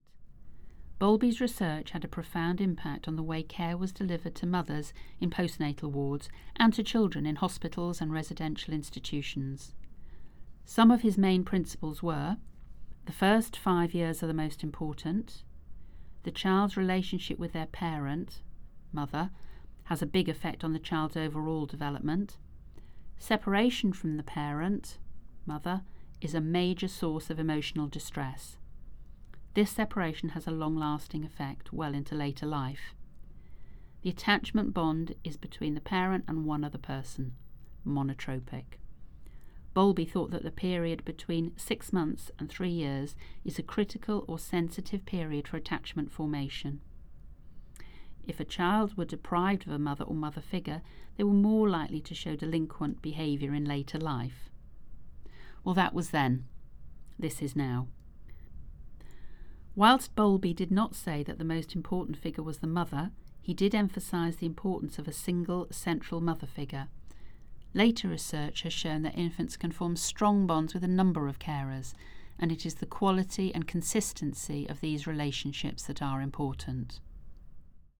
Narration audio